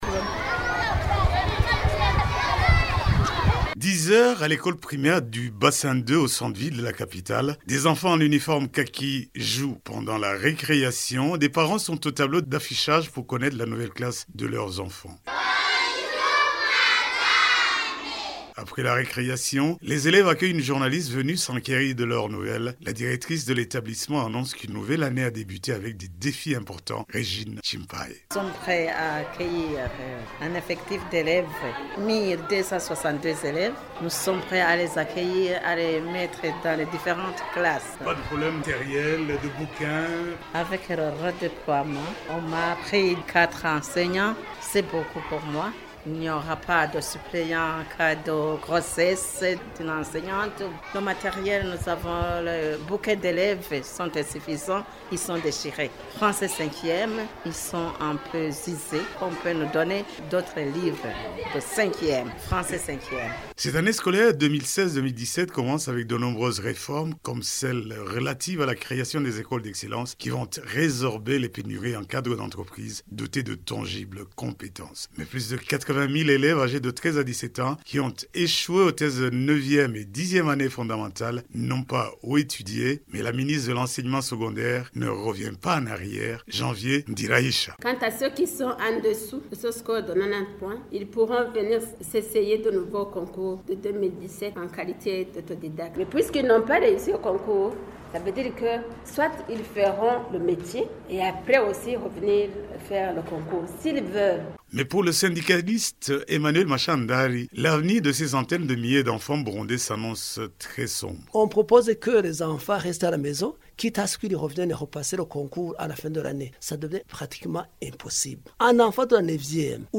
Notre correspondant à Bujumbura
Reportage